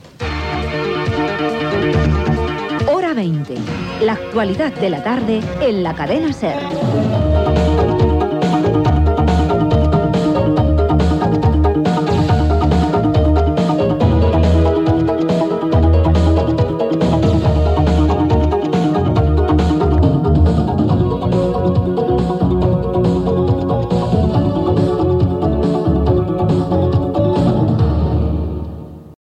Indicatiu del programa i capçalera musical